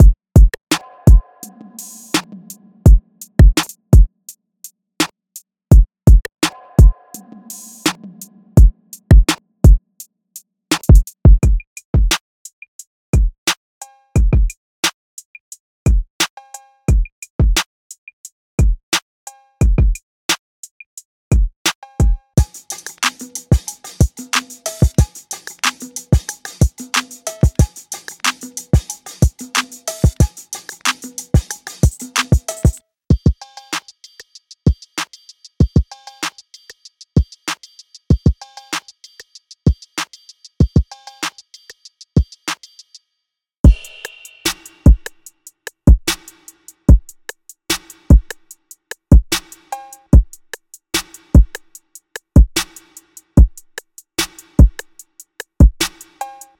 Carefully designed and processed samples with a heavy influence from the sound of West Coast and Bay Area music. Smooth and jazzy chord progressions, combined with other instruments will give you a perfect start to create something unique and original.
All samples include all the separate elements such as analogue synth leads, pads, pianos, guitars, basslines, FX, and more.